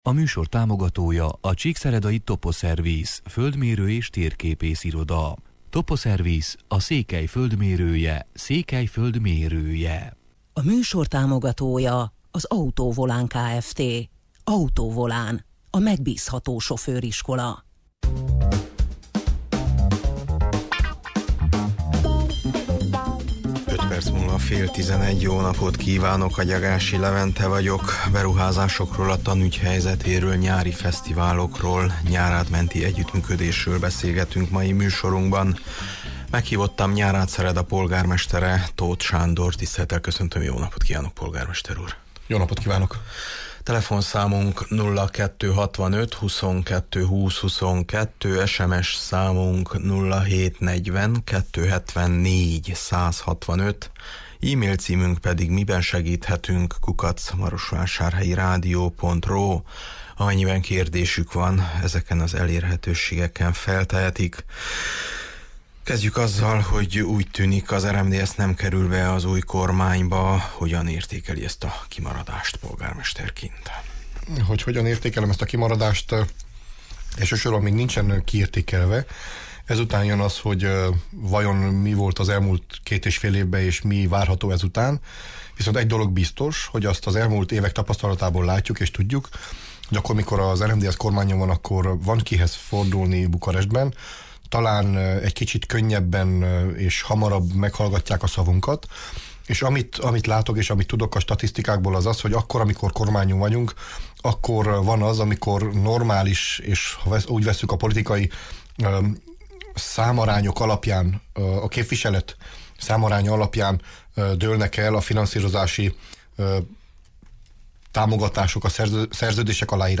Műsorunkban a kisváros polgármesterével, Tóth Sándorral beszélgetünk beruházásokról, a tanügy helyzetéről, nyári fesztiválokról, nyárád-menti együttműködésről: